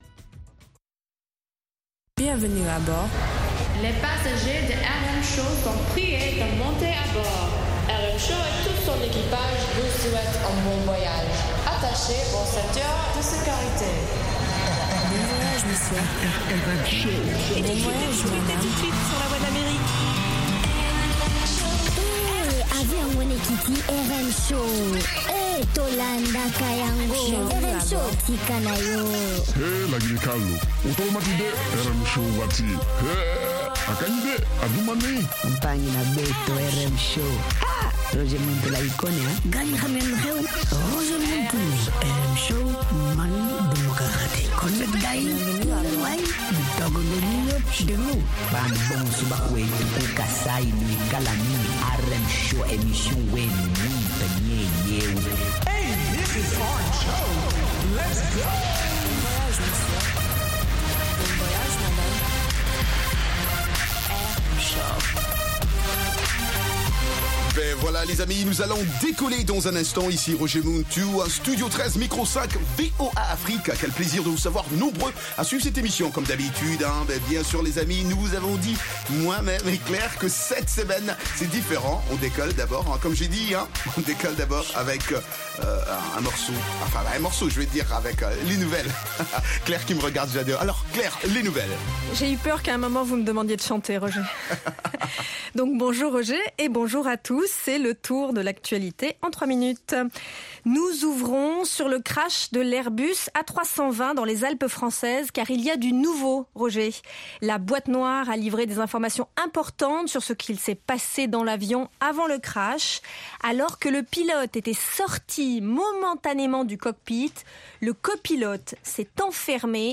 propose notamment d'écouter de la musique africaine